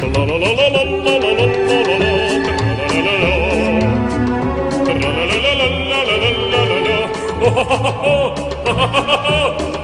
Category: Sound FX   Right: Both Personal and Commercial
Tags: meme sound; meme effects; youtube sound effects;